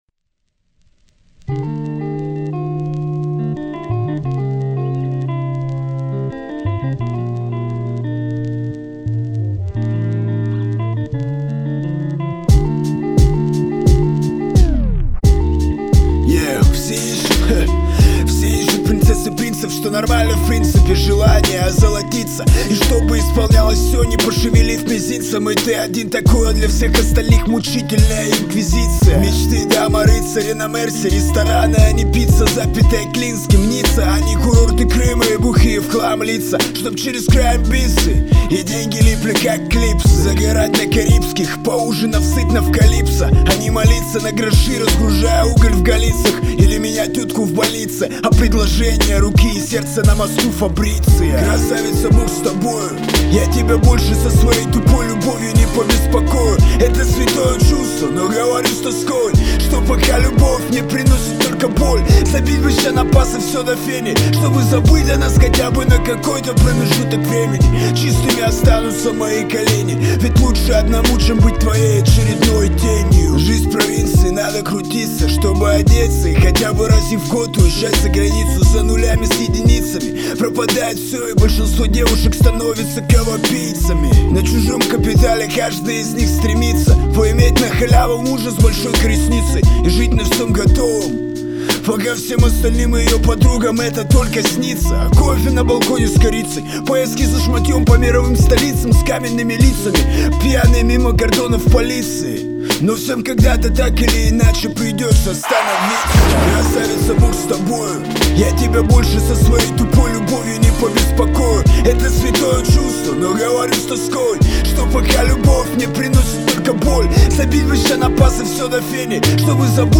Категория: RAP, R&B